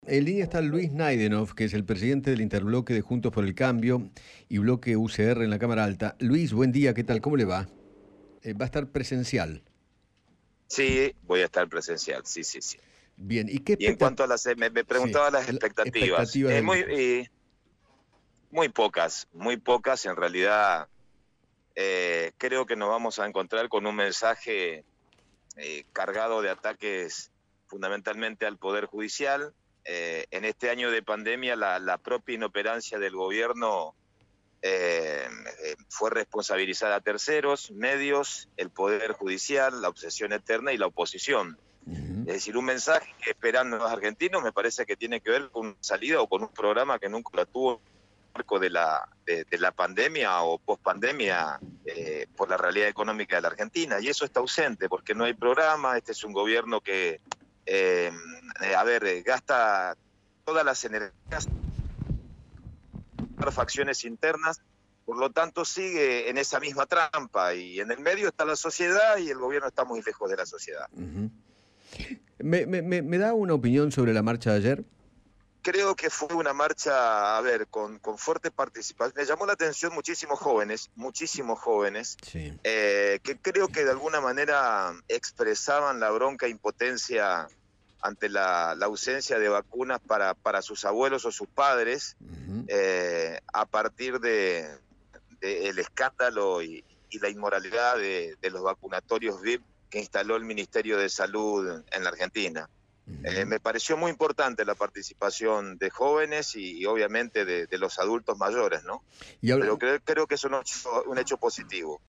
Luis Naidenoff, senador nacional, dialogó con Eduardo Feinman sobre la marcha que se realizó este sábado contra el Gobierno y se refirió a la apertura de las sesiones extraordinarias del Congreso.